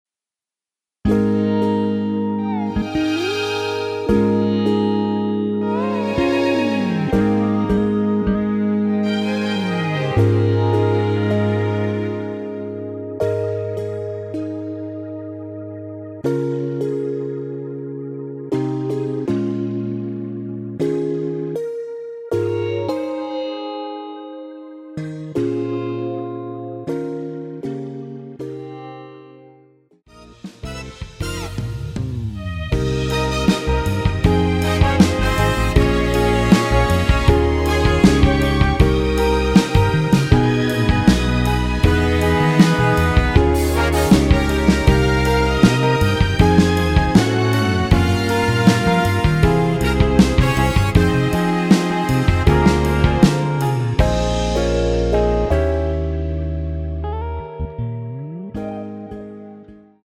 Ab
앞부분30초, 뒷부분30초씩 편집해서 올려 드리고 있습니다.
중간에 음이 끈어지고 다시 나오는 이유는